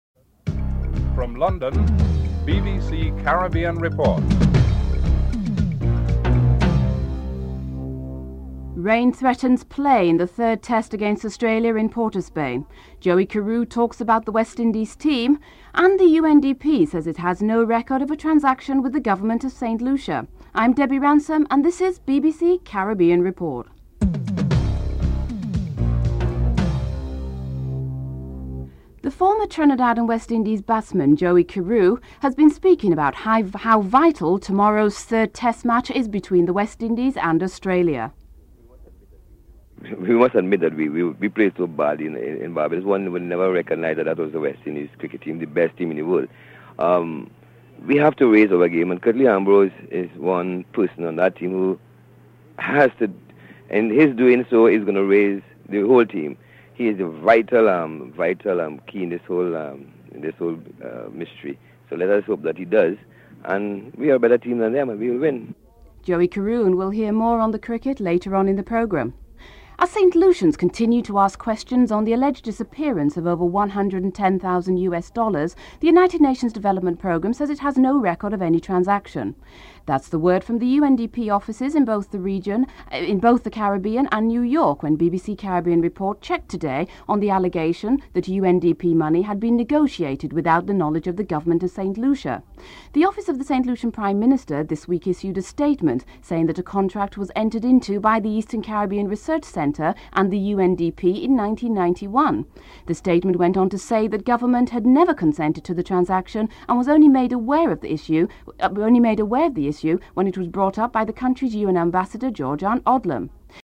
Caribbean Report took to the streets of Castries for a taste of public opinion on this issue.